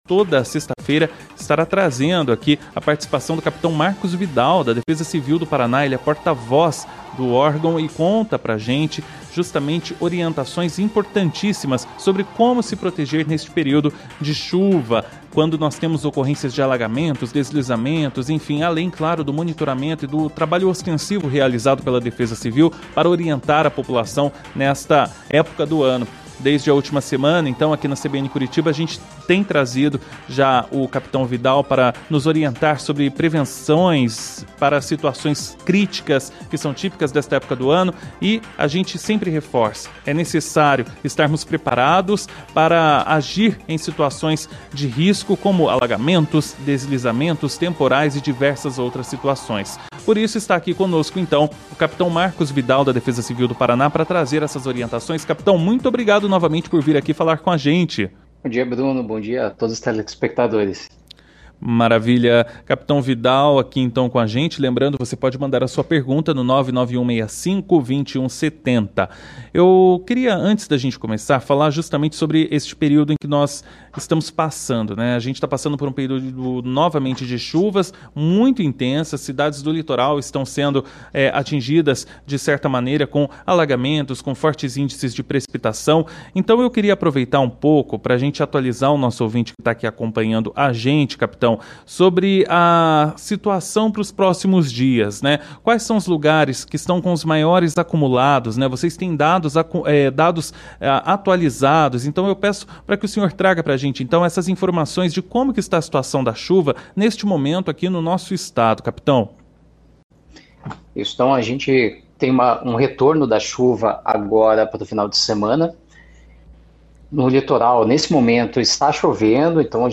Durante a série de entrevistas para a CBN Curitiba sobre as orientações para prevenir situações críticas durante o período de chuvas